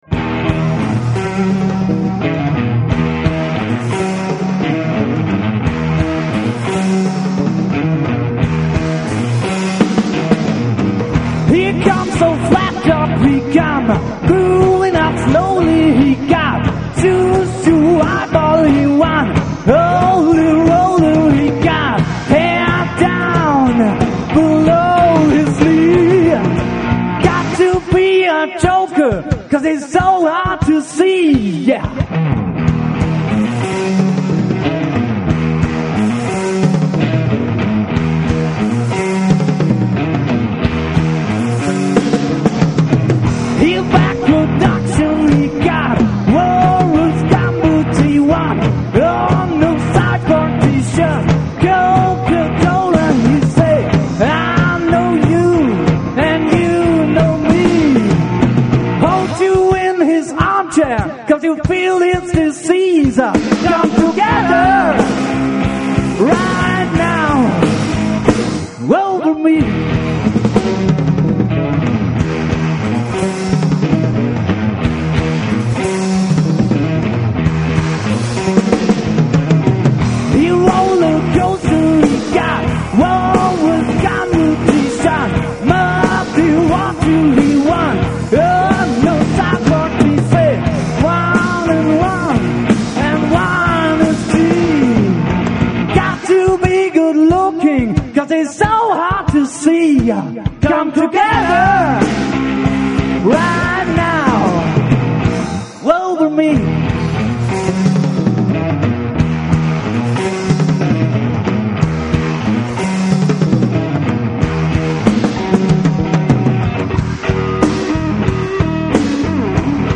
Live im Hotel Drei Könige Chur 1996, Vocals & Bass